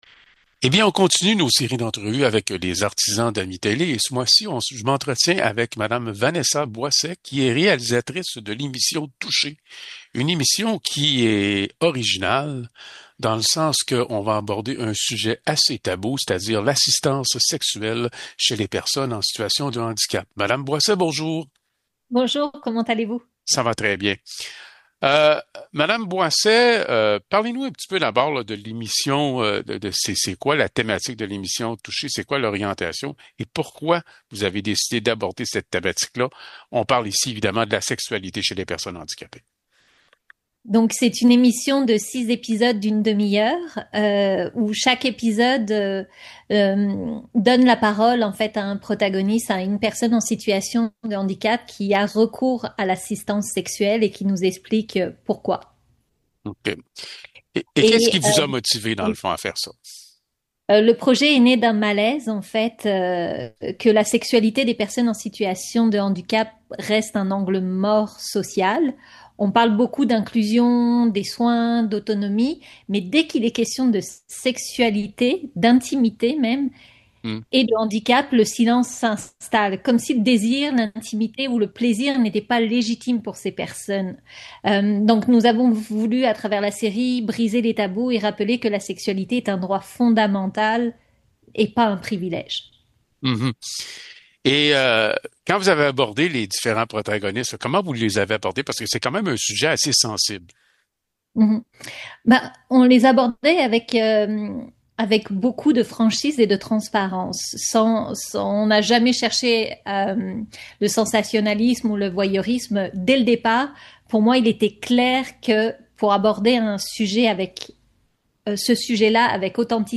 Nous continuons nos entrevues avec les artisans d’AMI-Télé.